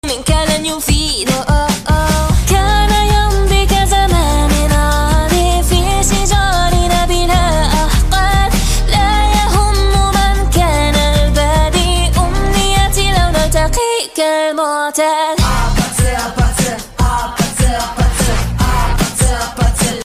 girl... that voice is FIRE!!!!